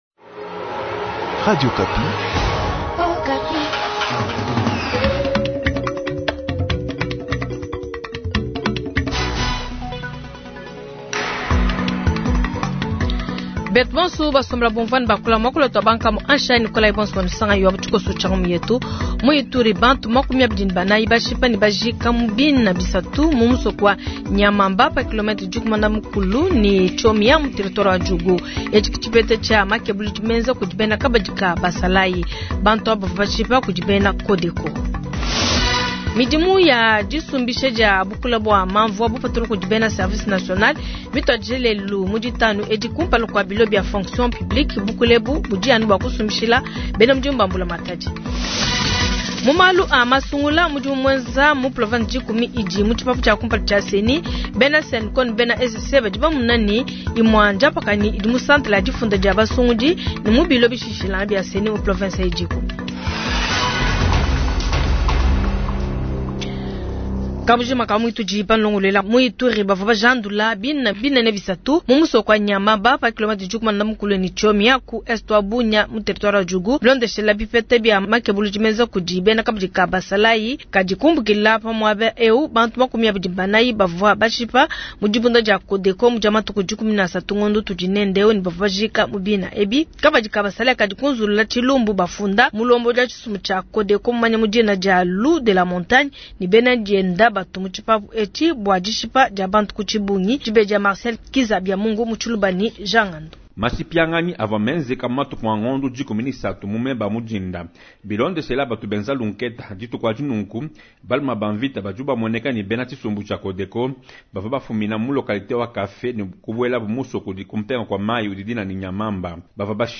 Jounal soir